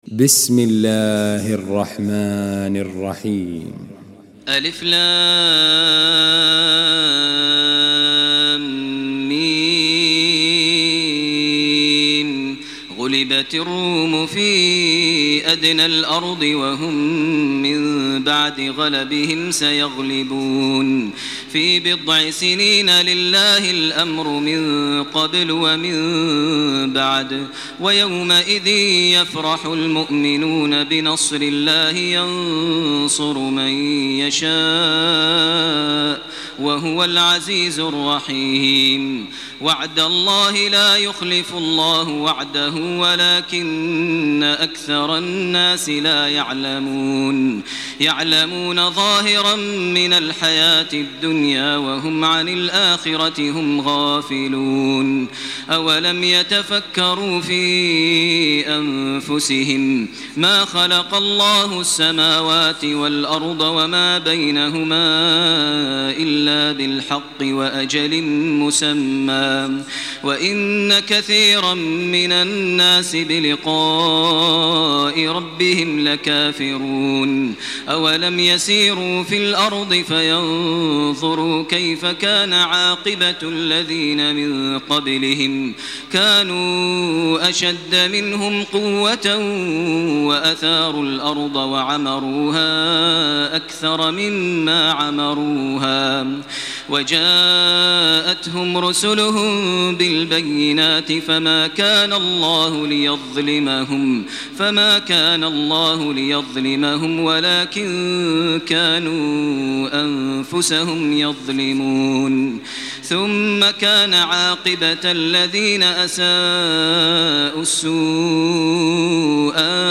تراويح ليلة 21 رمضان 1428هـ من سور الروم و لقمان و السجدة Taraweeh 21 st night Ramadan 1428H from Surah Ar-Room and Luqman and As-Sajda > تراويح الحرم المكي عام 1428 🕋 > التراويح - تلاوات الحرمين